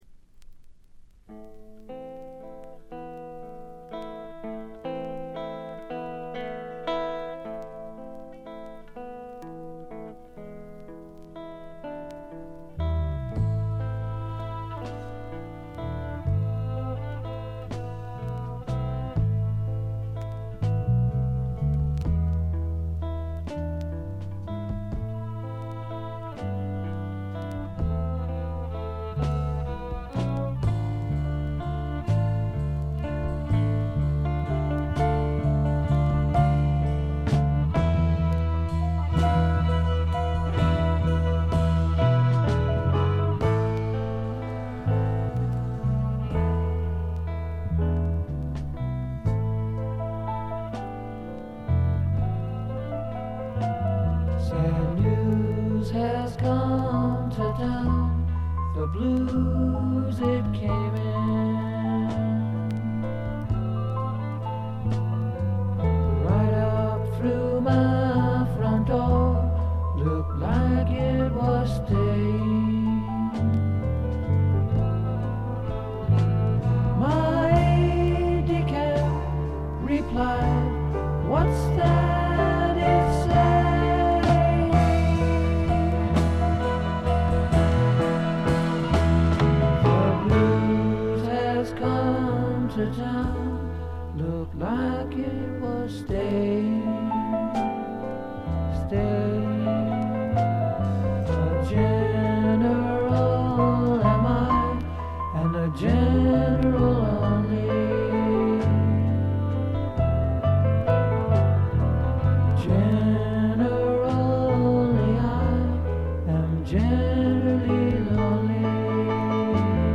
軽微なバックグラウンドノイズ。散発的なプツ音が少し。
試聴曲は現品からの取り込み音源です。